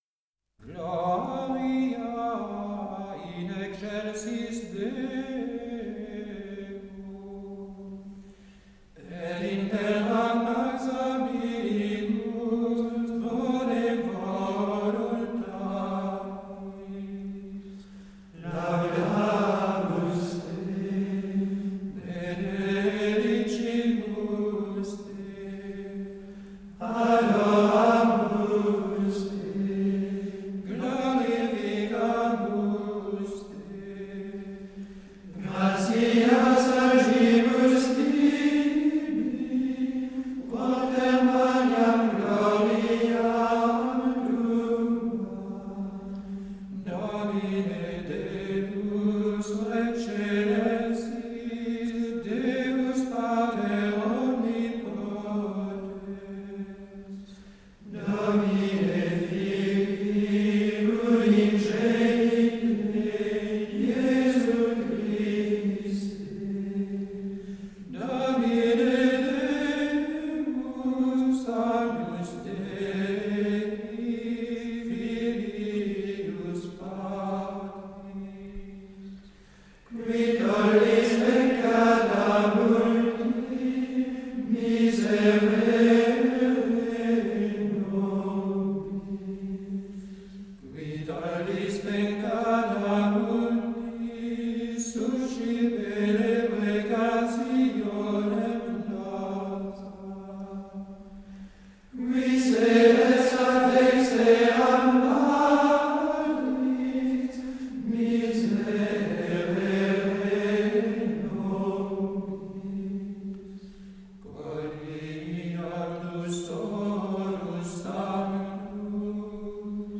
Il est pourtant très simple et presque syllabique, assez répétitif, mais muni de multiples petites variations qui lui donnent un charme incontestable. Repéré dans des manuscrits nombreux du XIIe siècle, et probablement d’origine allemande, il emprunte sa mélodie au 4e mode, ce qui ajoute sans doute à sa beauté profonde, mystique.
La suite est très simple, et on l’a dit, quasiment syllabique.
Alors que l’ensemble de la pièce est souverainement léger, le Amen est quant à lui bien planté en terre et en chair.
Gloria-12-Solesmes.mp3